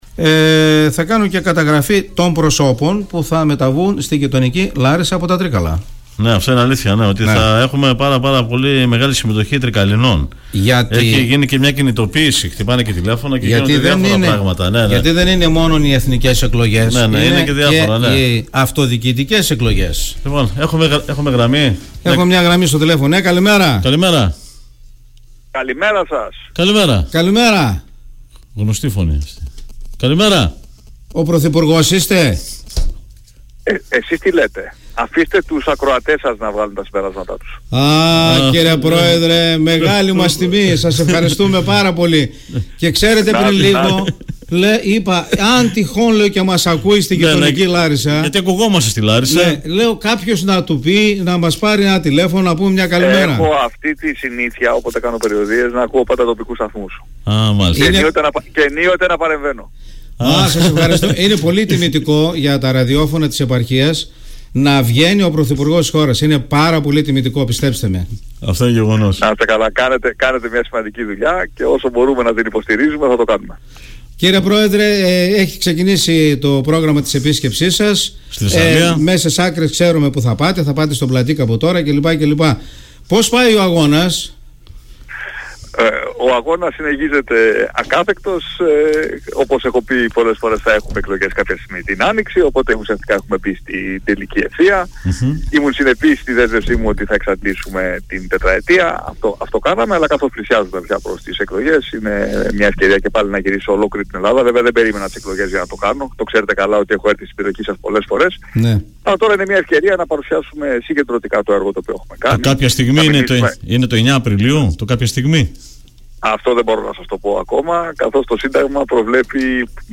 Μετά την πρωινή ραδιοφωνική συνέντευξη ήρθε και η συνάντηση από κοντά